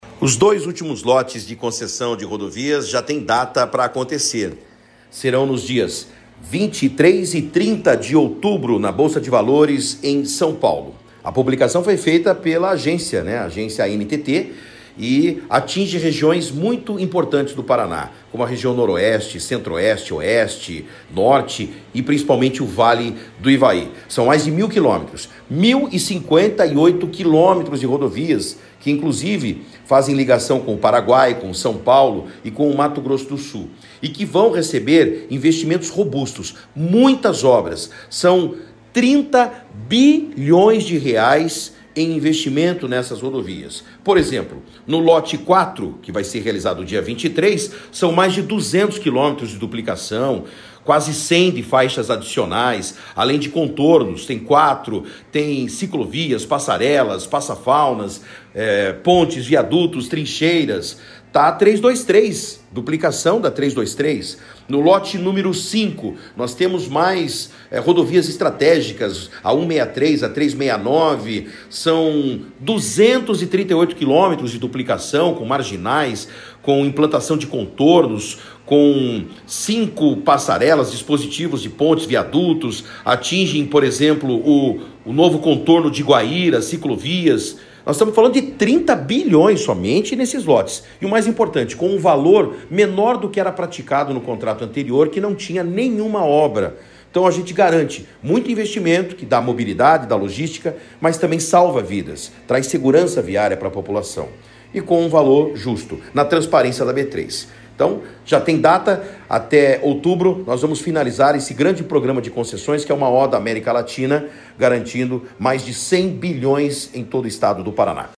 Ouça o que disse o secretário de Infraestrutura e Logística do Paraná, Sandro Alex: